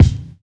INSKICK10 -R.wav